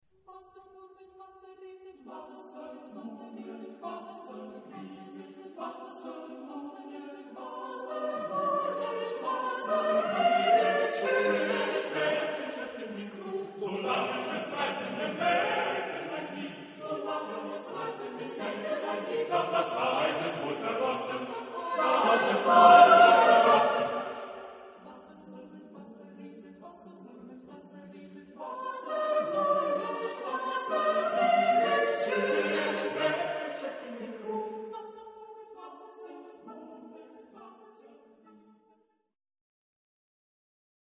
Genre-Style-Forme : Cycle ; Pièce chorale ; Profane
Type de choeur : SATBB  (5 voix mixtes )
Tonalité : libre